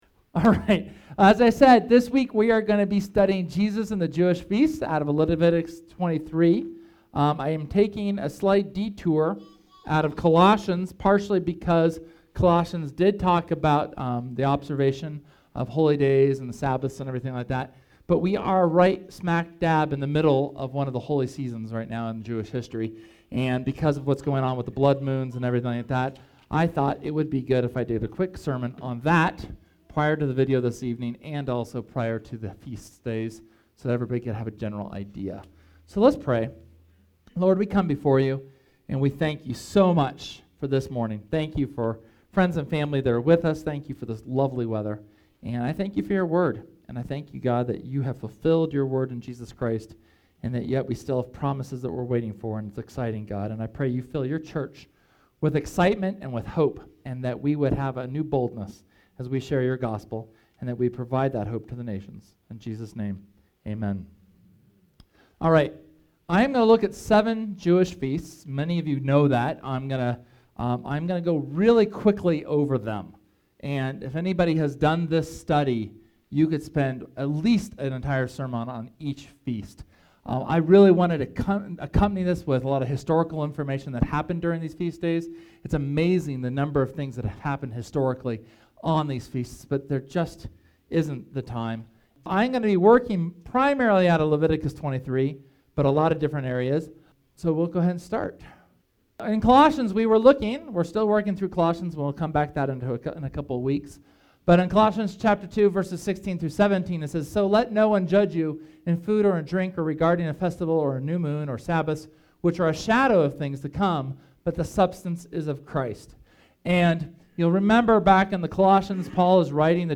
SERMON: Jesus in the Jewish Feats